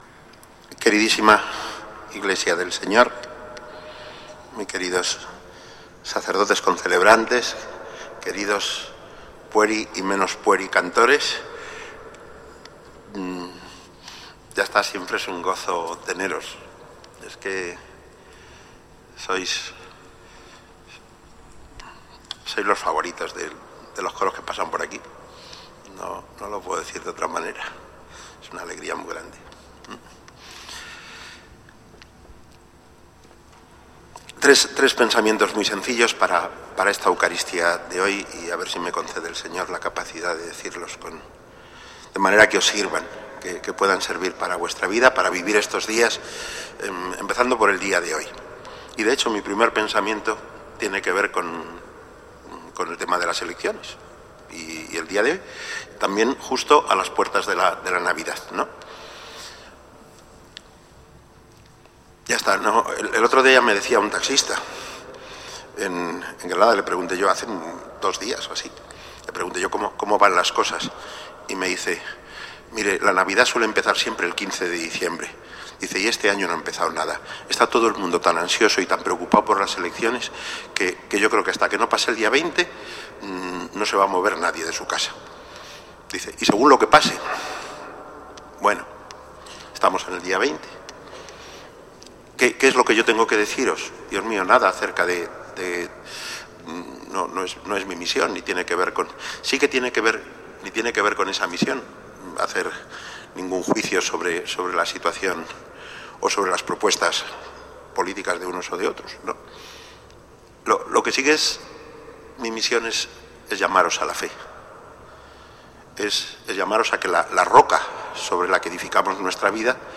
Homilía en el IV Domingo de Adviento, en la S.I Catedral, en la Eucaristía en la que también el Arzobispo bendijo el Belén del templo catedralicio.
Homilia_Mons._Martinez_IV_Domingo_Adviento_S.I._Catedral.mp3